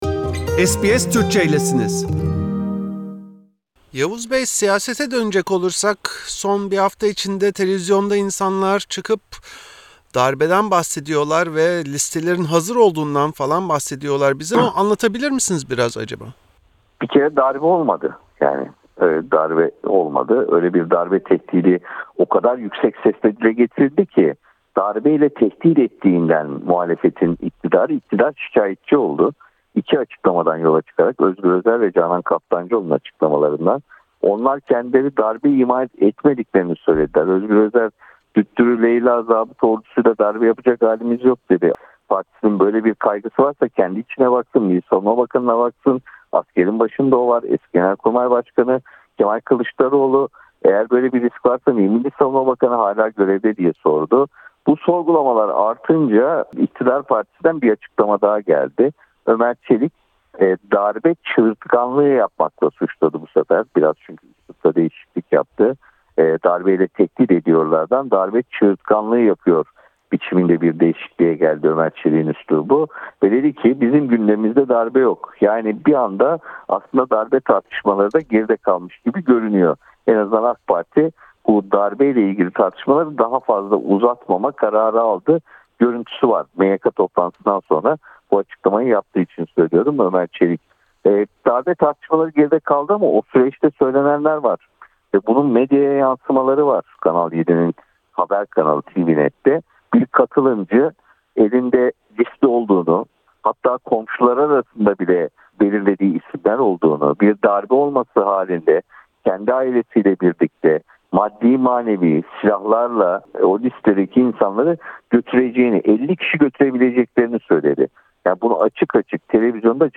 Gazeteci Yavuz Oğhan, muhalefeti darbe ile tehdit etmekle suçlayan iktidarın, bir söylem değişikliğine giderek son günlerde muhalefeti darbe çığırtkanlığı ile suçladığını söyledi. Öte yandan, ne televziyonda komşularını tehdit eden kişinin ne de onu destekleyen moderatörün cezalandırıldığını söyledi.